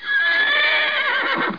00712_Sound_whinny.mp3